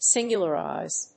音節sin・gu・larize 発音記号・読み方
/síŋgjʊlərὰɪz(米国英語)/